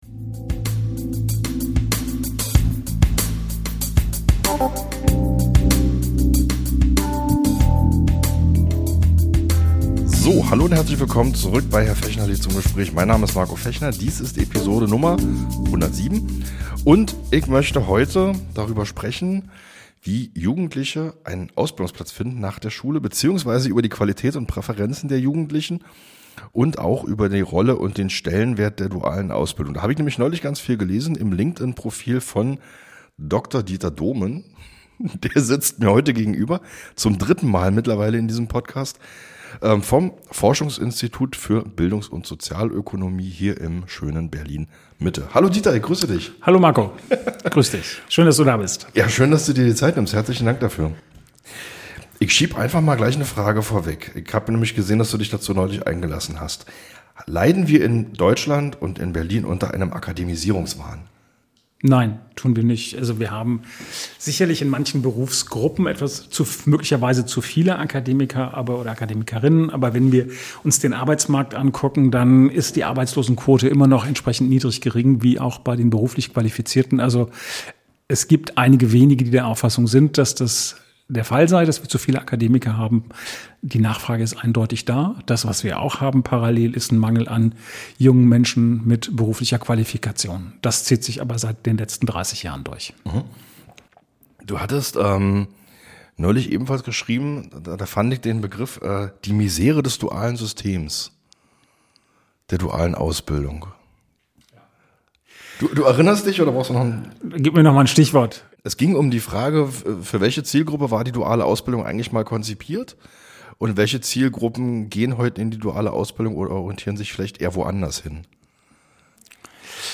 Dieses Gespräch ist kein Jammern über „die Jugend von heute“, sondern ein analytischer Blick auf strukturelle Versäumnisse.